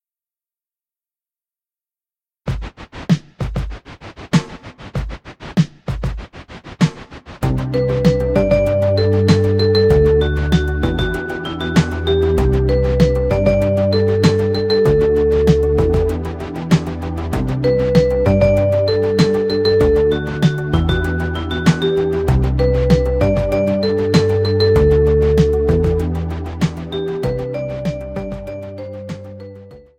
Voicing: Cello and Audio Online